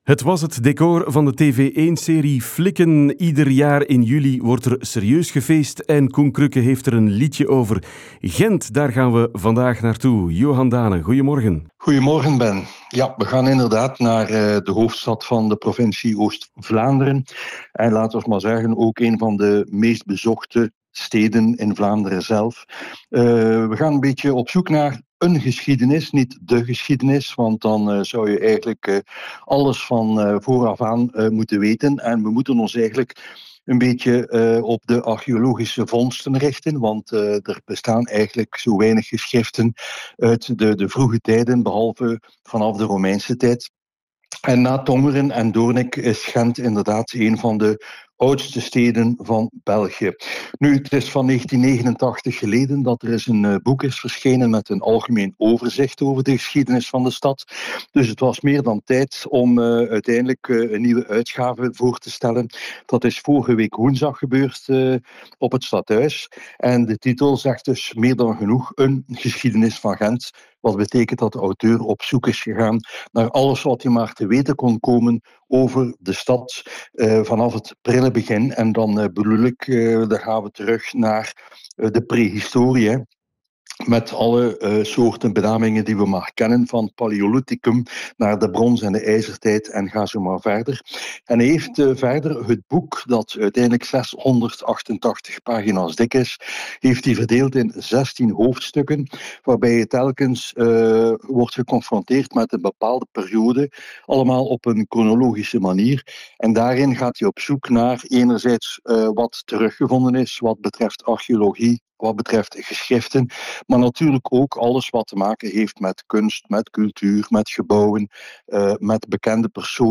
over het boek op Radio Rand / Radia Meteor